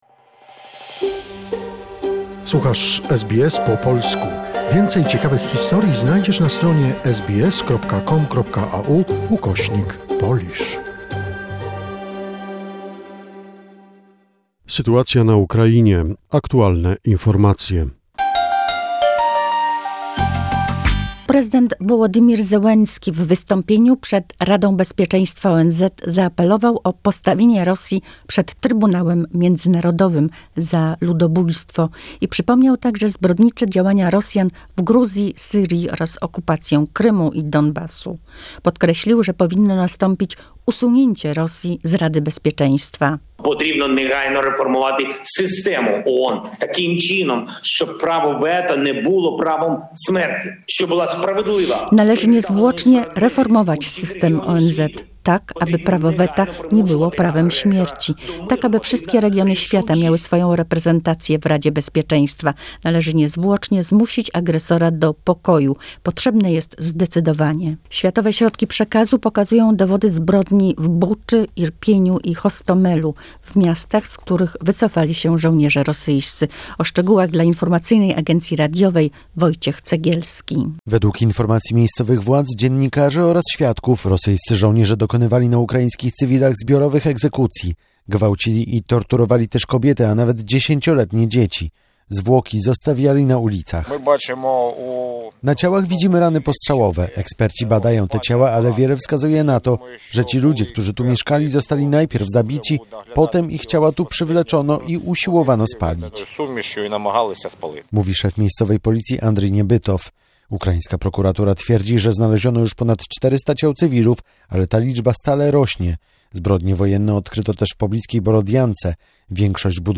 The most recent information about the situation in Ukraine, a short report prepared by SBS Polish